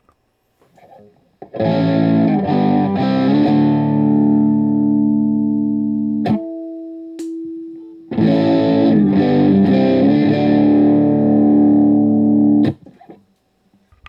All recordings in this section were recorded with an Olympus LS-10. Amp is an Axe-FX Ultra set to “Basic Brit 800” with no effects. Speaker is a QSC K12.
Playing with both pickups enabled leads to the need to play around with the phase switch, first in normal mode, and then with the phase switch enabled.
On this guitar, it really adds a nicer thinner sound without the nasally aspect I normally associate with out-of-phase humbuckers.
Guild-97-S100-PhaseSwitch.wav